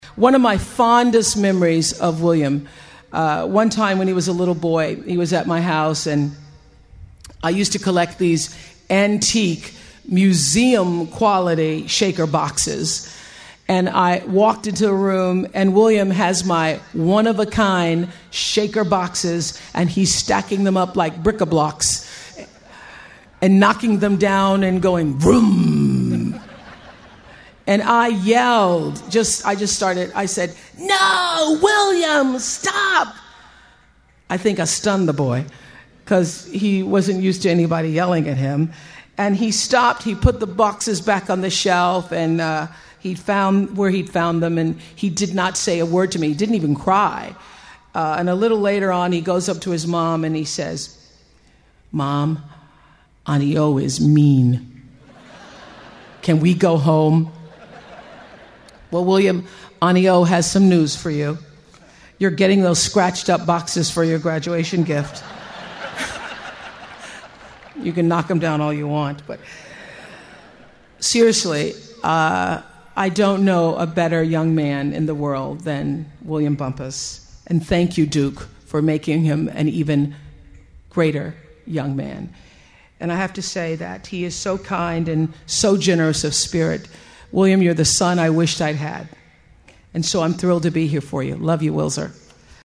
名校励志英语演讲 73:追随自己的心声,你们一定会成功 听力文件下载—在线英语听力室